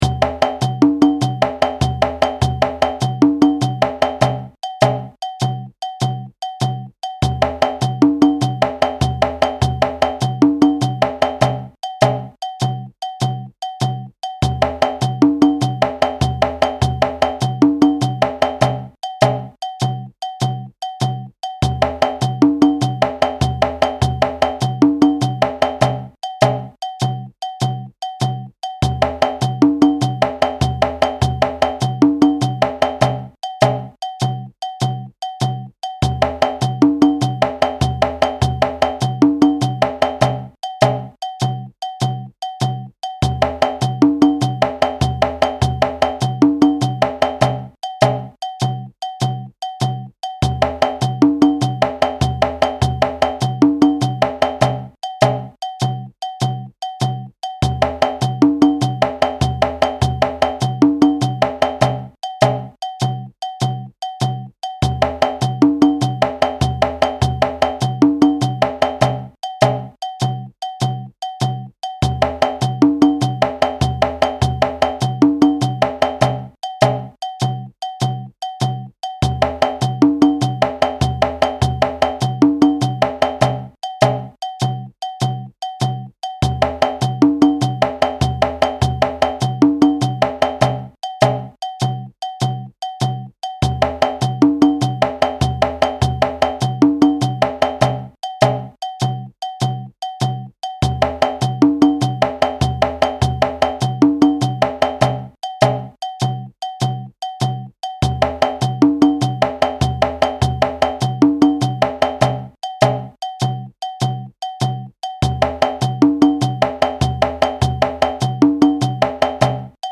This phrase represents a typical 2-bar phrase found in traditional djembe music.
audio (with shekeré, bass & bell)
This impressive collection of 2-Bar Special Phrases (60) are drawn from djembe solo settings and were chosen for their inherent “call and response” qualities as well as for their ability to teach the drummer how to dance around the pulse.